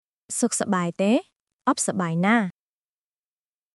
解説→→（ソック（良い） サバーイ（元気） テー（ですか？） オッ（ない） サバーイ（元気） ナー（ですよ？））
当記事で使用された音声（クメール語および日本語）は全てGoogle翻訳　および　Microsoft TranslatorNative Speech Generation、©音読さんから引用しております。